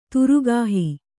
♪ turugāhi